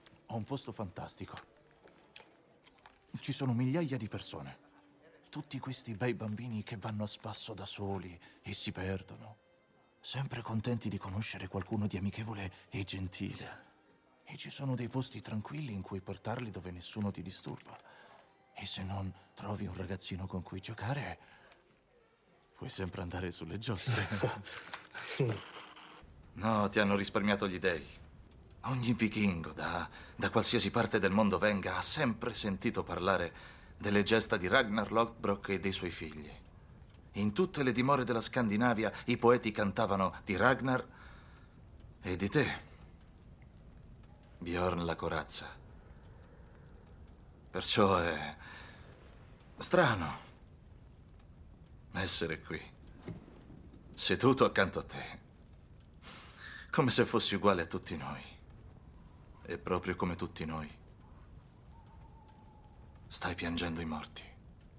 nel telefilm "The Sandman", in cui doppia Danny Kirrane, e nel telefilm "Vikings", in cui doppia Eric Johnson.